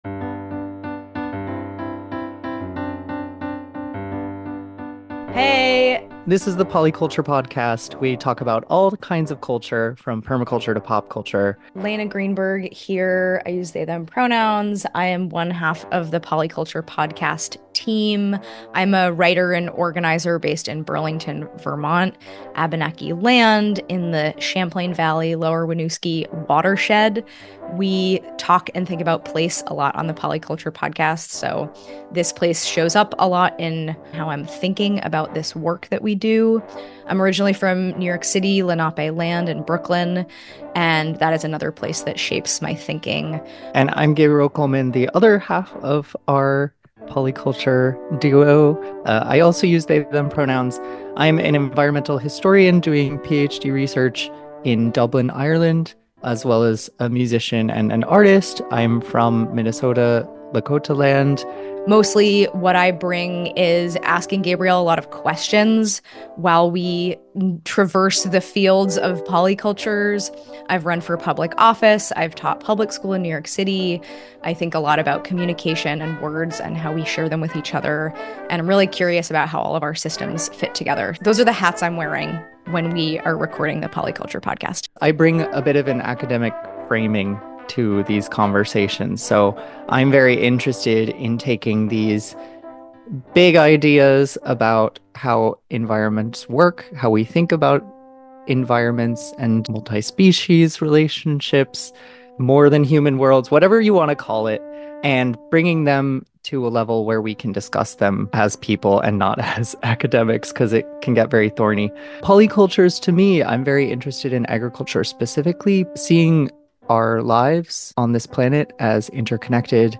Trailer
Polyculture-Trailer.mp3